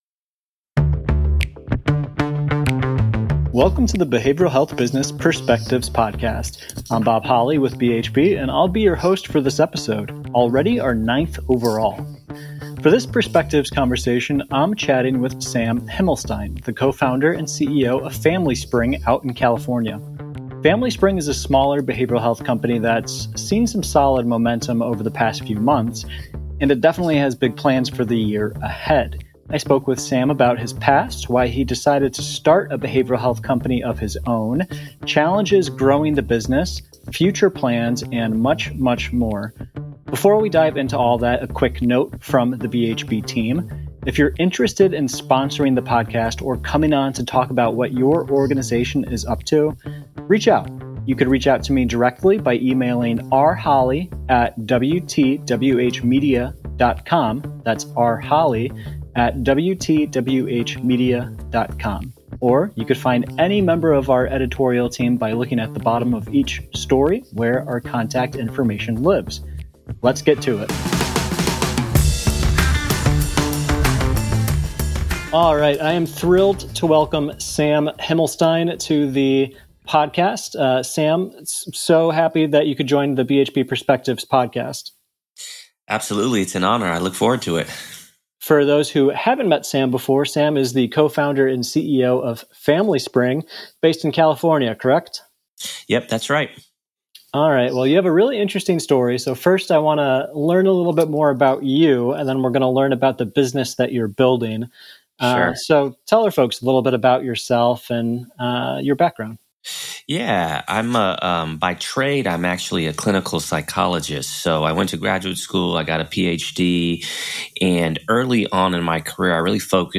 Subscribe to get the latest candid and exclusive executive interviews in your Soundcloud,